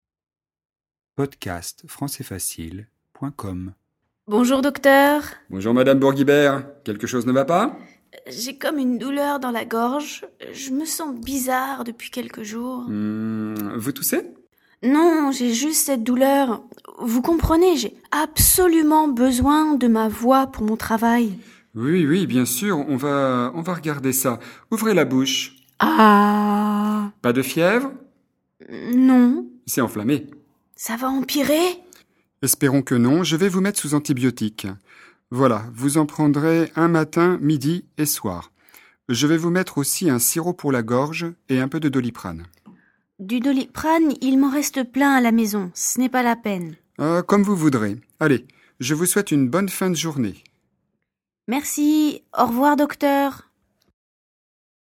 Dialogue FLE et exercices de compréhension, niveau intermédiaire (A2) sur le thème de la santé.
🔷 DIALOGUE :